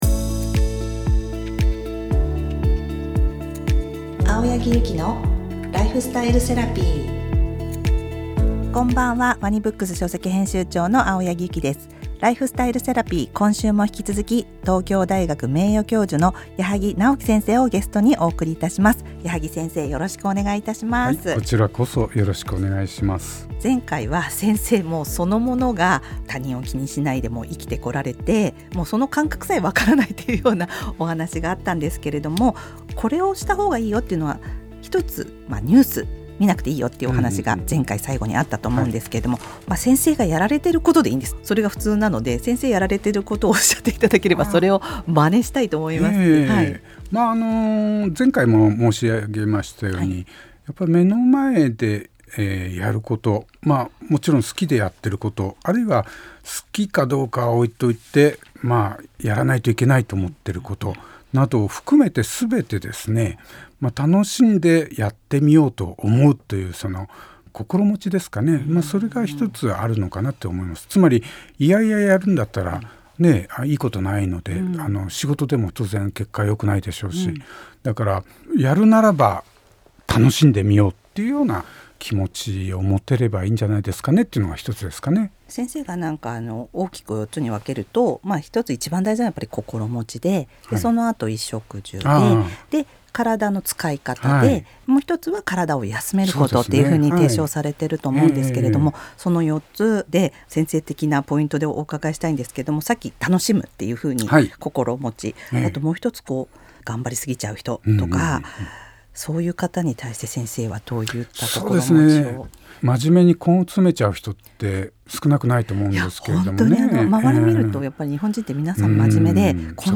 ライフスタイルセラピー「the ANSWER」今回のゲストは、東京大学名誉教授の矢作直樹先生をお招きしました。